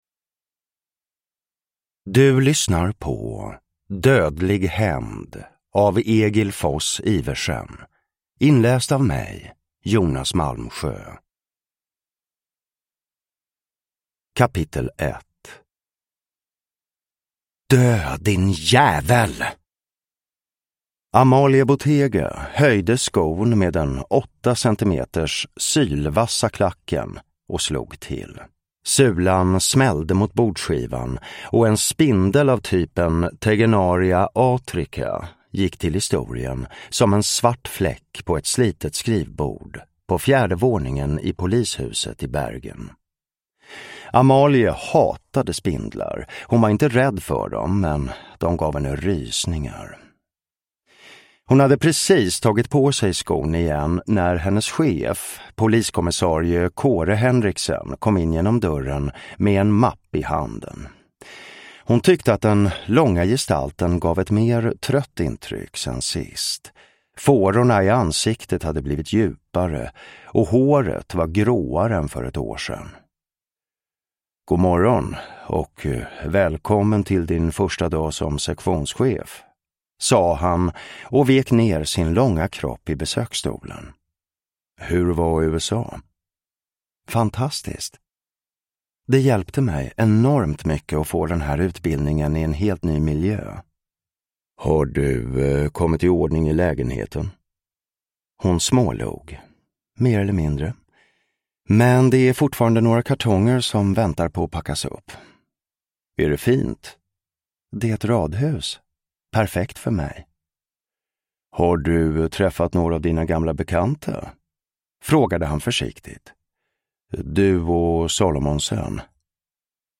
Dödlig hämnd (ljudbok) av Egil Foss Iversen